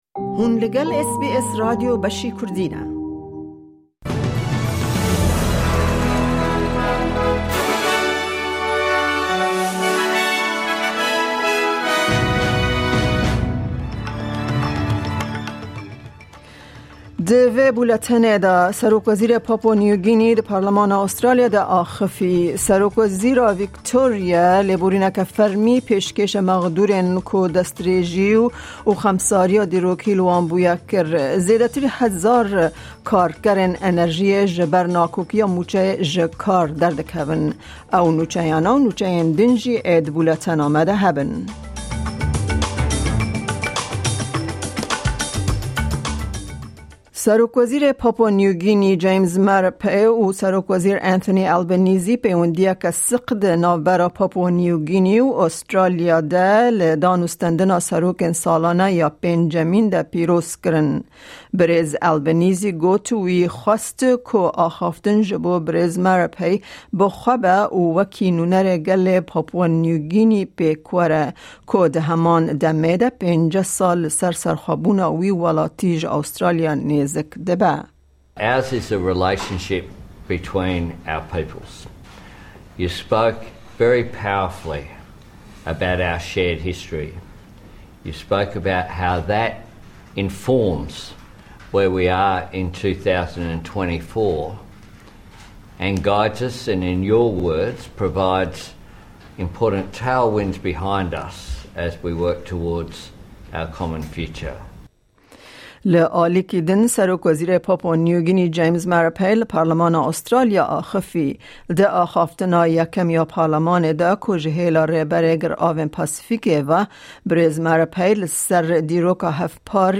Nûçeyên roja Pêncşemê 8î Şubata 2024